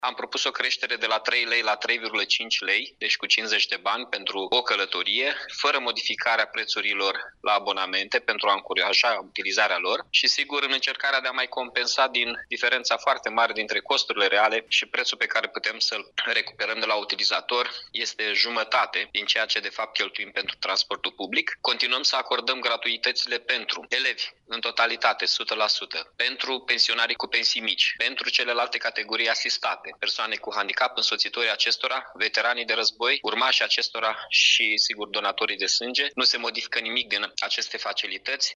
Abonamentele vor rămâne la același preț, a precizat, primarul Mihai Chirica: Am propus o creștere de la 3 lei la 3,5 lei, deci cu 0,50 RON pentru o călătorie fără modificarea prețurilor la abonamente, pentru a încuraja utilizarea lor și, sigur, în încercarea de a mai compensa din diferența foarte mare dintre costurile reale și prețul pe care putem să-l recuperăm de la utilizator, este jumătate din ceea ce de fapt cheltuim pentru transportul public.